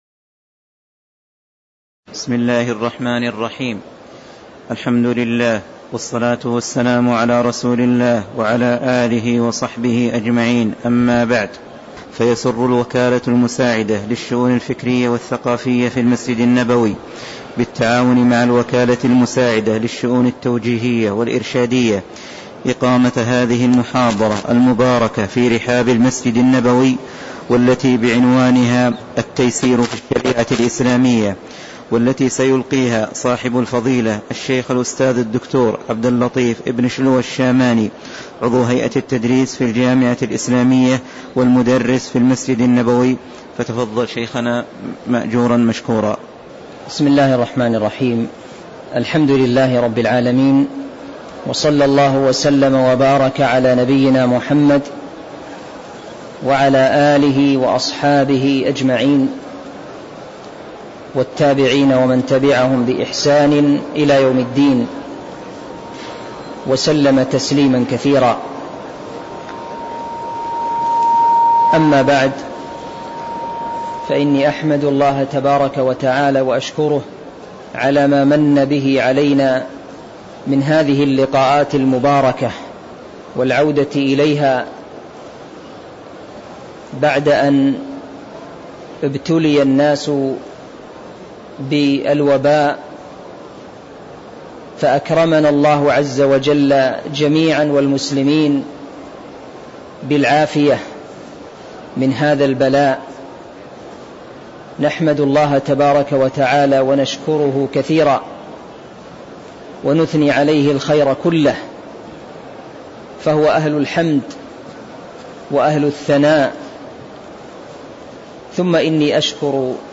تاريخ النشر ١٢ رمضان ١٤٤٣ هـ المكان: المسجد النبوي الشيخ